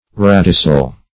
Search Result for " radicel" : The Collaborative International Dictionary of English v.0.48: Radicel \Rad"i*cel\ (r[a^]d"[i^]*s[e^]l), n. [Dim. of radix.]